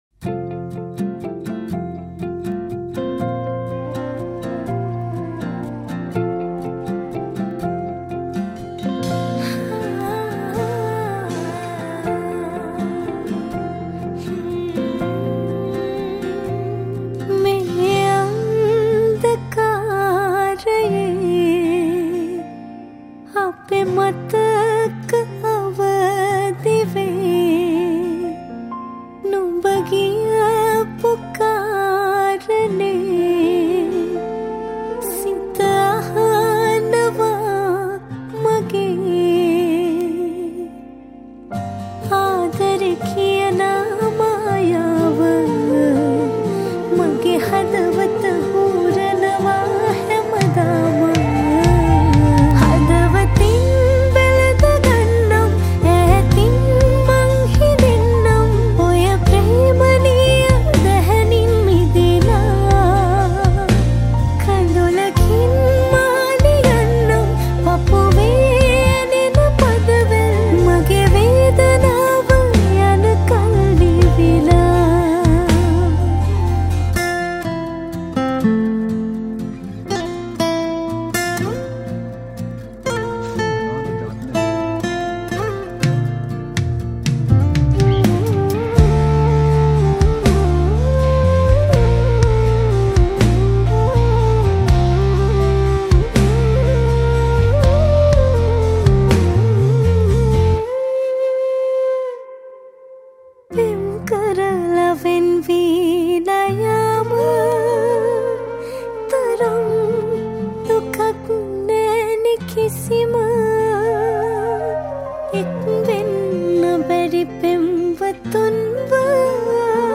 Flutist
Guitarist
Piano